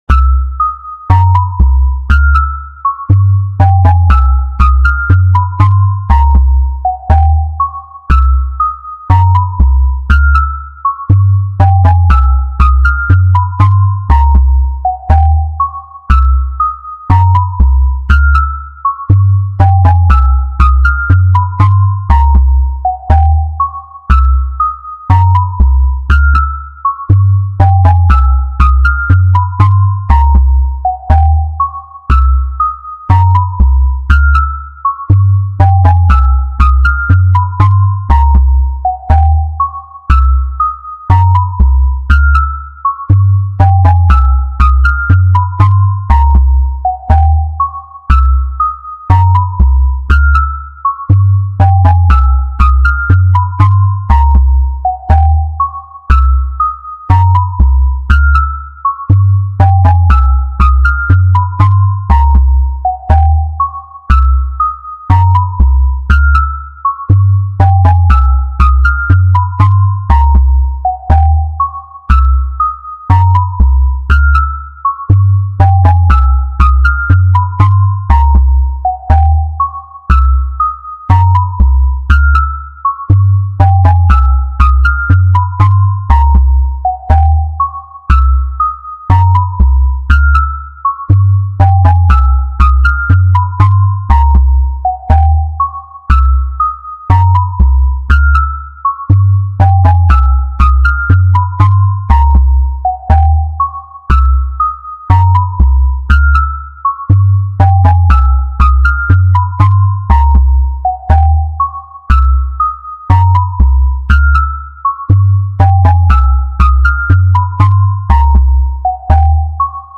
金属っぽい音。ホラー向け。
BPM150